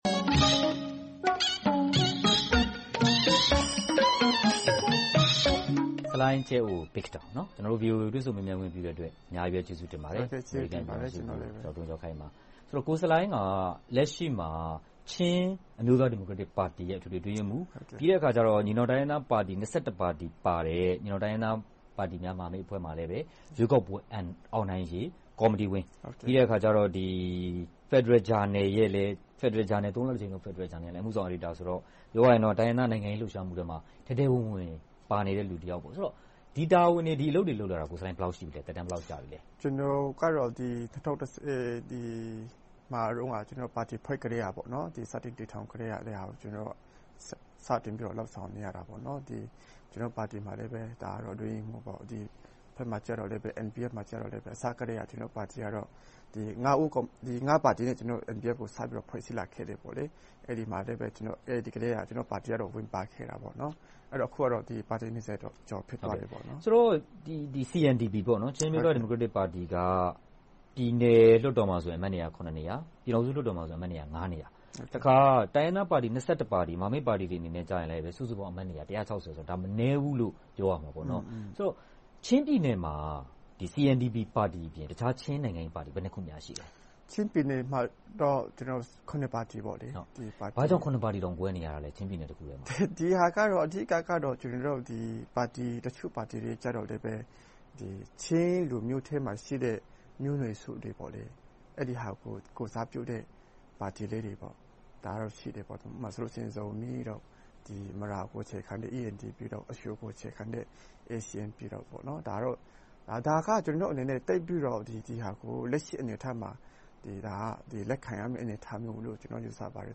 ချင်းအမျိုးသား ဒီမိုကရက်တစ်ပါတီ ခေါင်းဆောင်နဲ့ တွေ့ဆုံမေးမြန်းခန်း